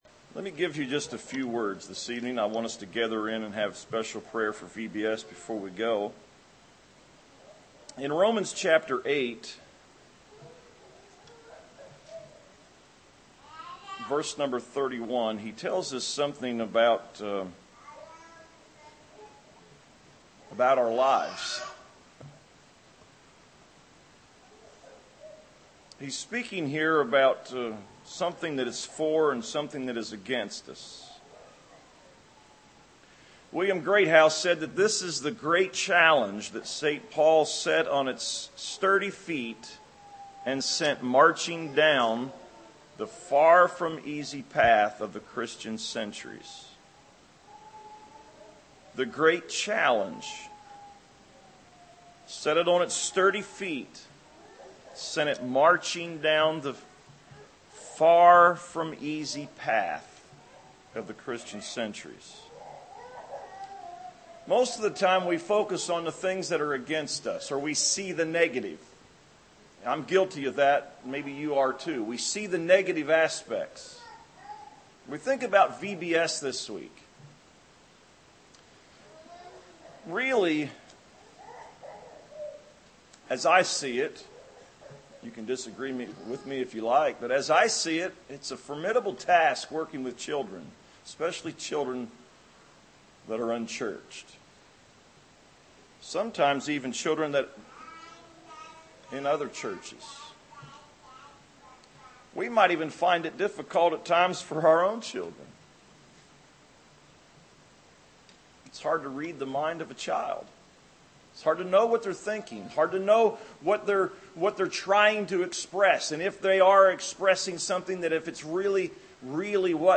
Tagged with vacation bible school , vbs